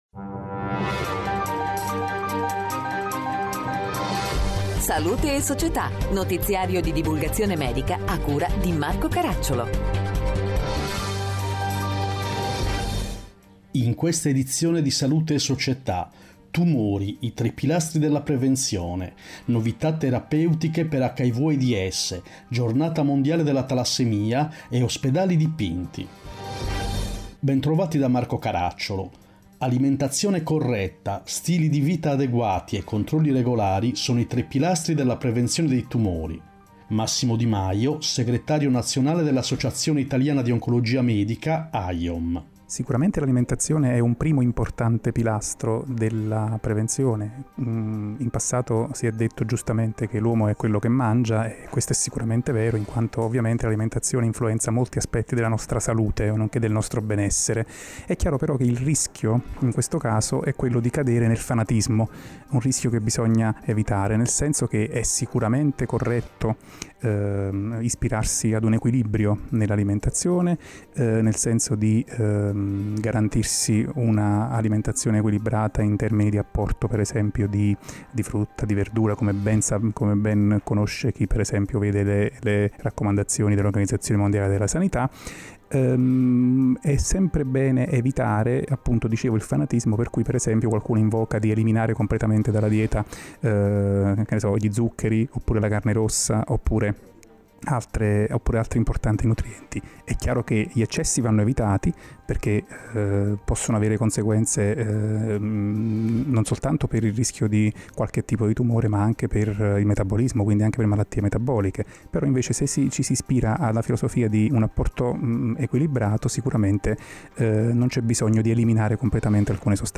In questa edizione: 1. Tumori, I tre pilastri della prevenzione 2. Novità terapeutiche per l’HIV/AIDS 3. Giornata Mondiale della Talassemia 4. Pediatria, Ospedali Dipinti Interviste